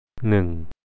redround.gif (1007 bytes)  The vowel  ʉ and ʉʉ.  (อือ)
หนึ่ง one nʉ̀ŋ